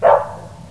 od_dogs3.wav